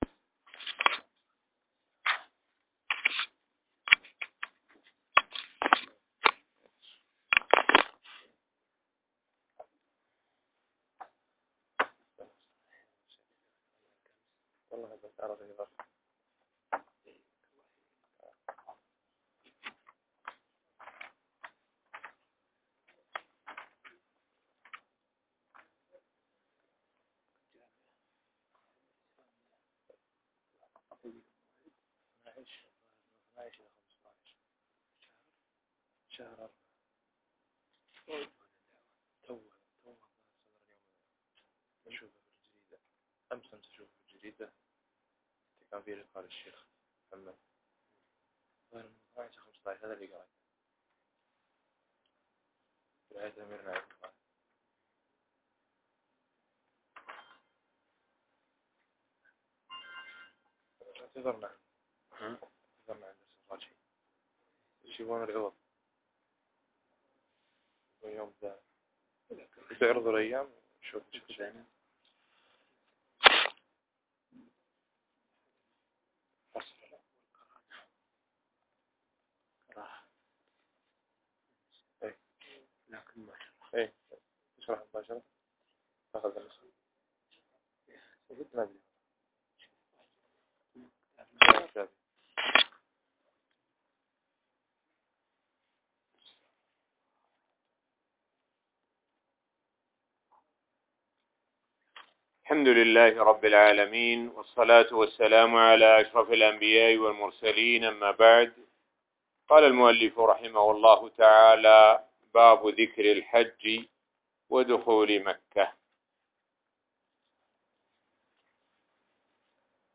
الموقع الرسمي لفضيلة الشيخ الدكتور سعد بن ناصر الشثرى | الدرس--21 باب ذكر الحج ودخول مكة